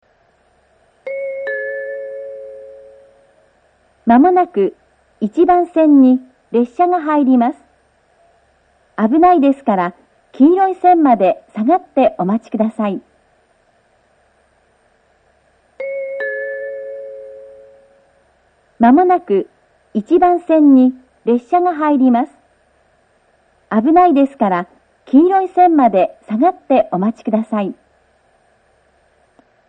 higashi-noshiro-1bannsenn-sekkinn.mp3